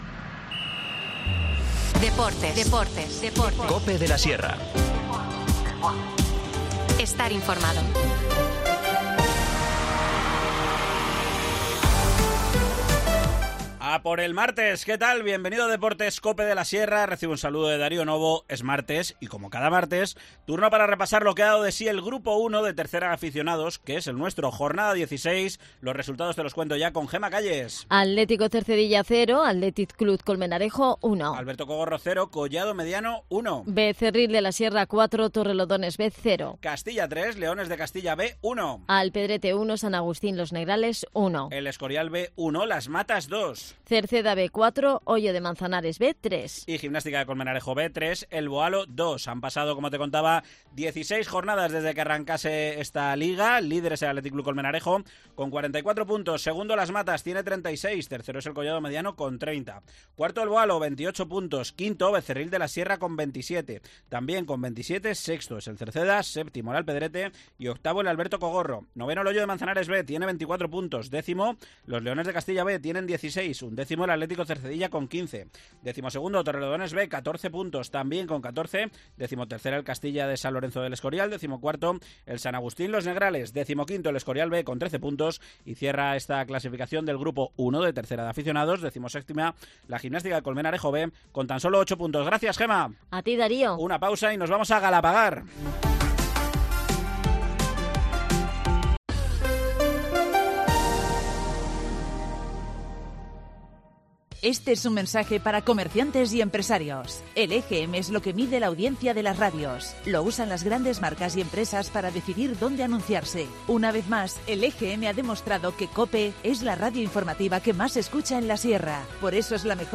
Nos lo cuenta su alcaldesa, Carla Greciano.